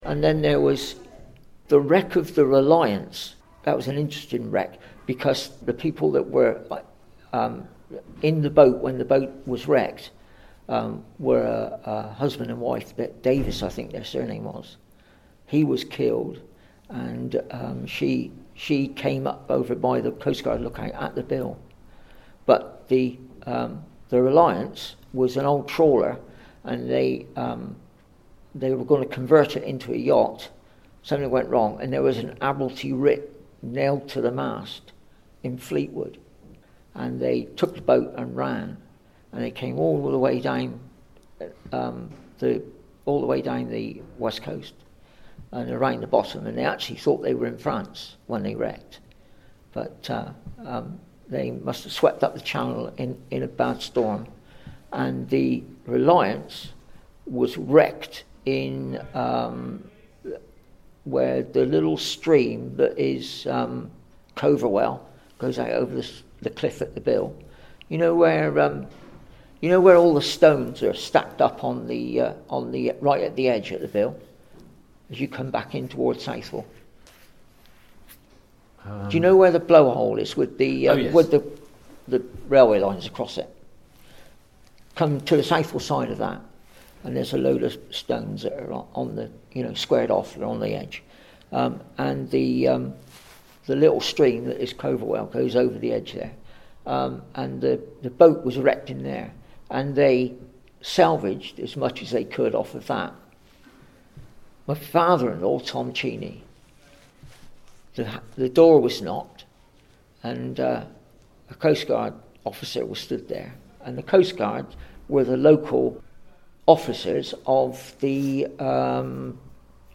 ABSOLUTE THEATRE in partnership with learners from the ISLE OF PORTLAND ALDRIDGE COMMUNITY ACADEMY, recorded these anecdotes, memories and experiences of Portland people.